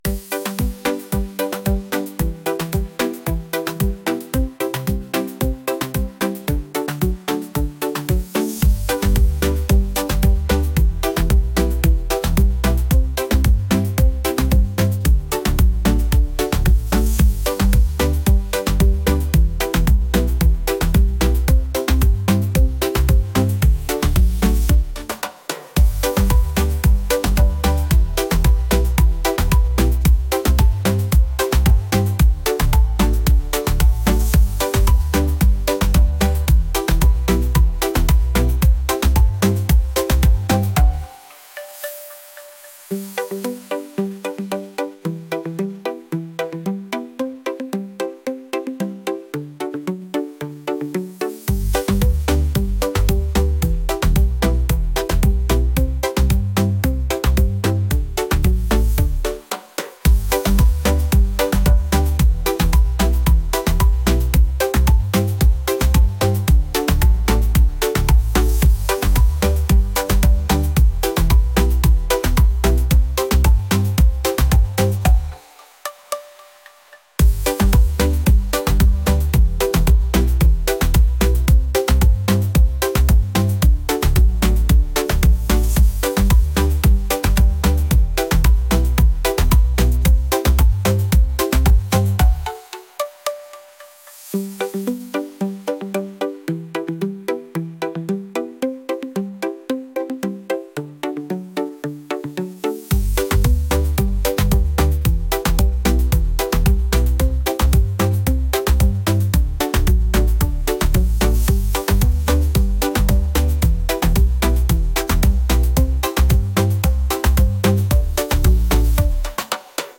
pop | reggae | latin